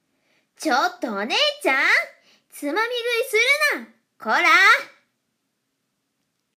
サンプルボイス ちょっとオカン 【少女】